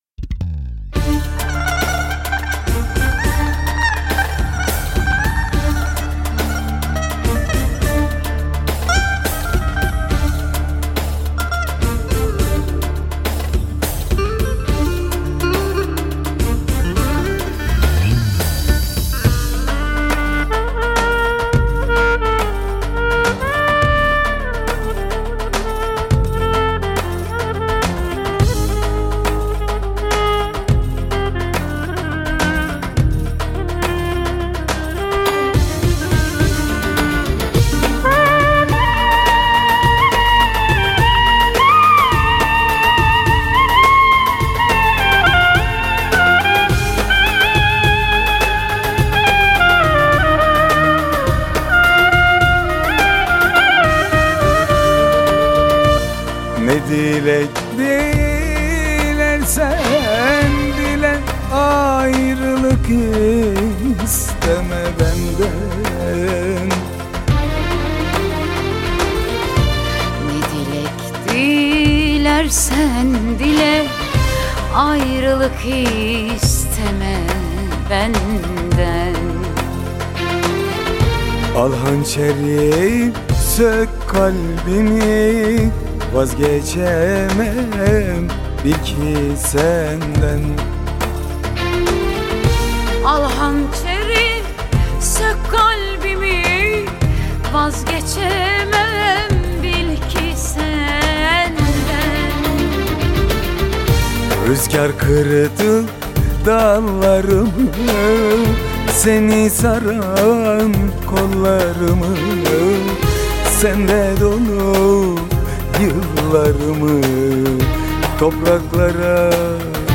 Eser Şekli : Pop Fantazi